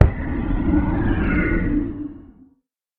Minecraft Version Minecraft Version snapshot Latest Release | Latest Snapshot snapshot / assets / minecraft / sounds / mob / guardian / elder_death.ogg Compare With Compare With Latest Release | Latest Snapshot
elder_death.ogg